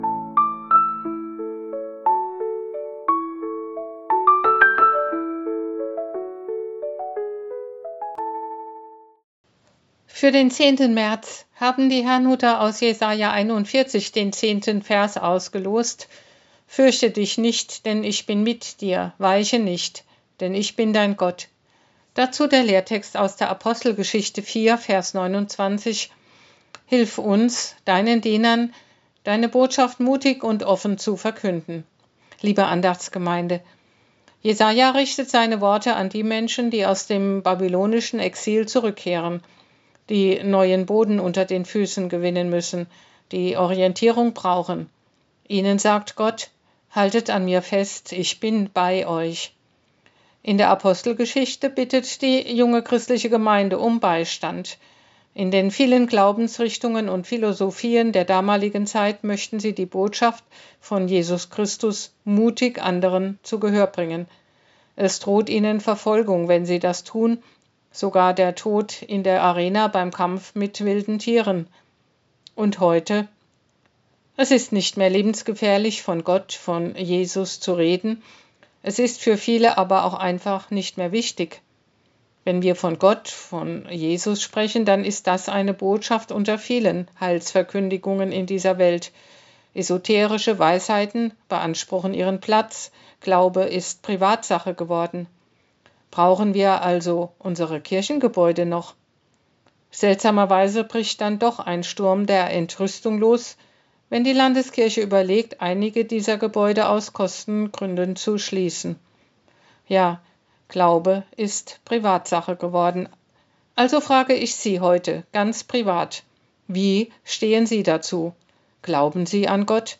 Losungsandacht für Dienstag, 10.03.2026 – Prot.